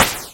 8Bit声音 " gun4
描述：第4支8Bit枪 请给我一个评论，我接下来要发出声音，谢谢:)。
标签： 射击 激光 炮弹 8位 步枪 射击 武器 军队 射击 射击
声道立体声